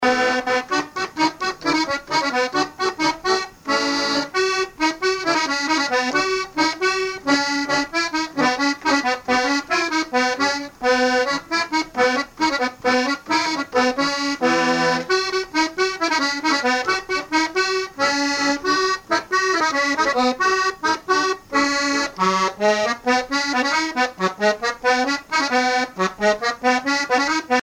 Divertissements d'adultes - Couplets à danser
branle : courante, maraîchine
musique à danser à l'accordéon diatonique
Pièce musicale inédite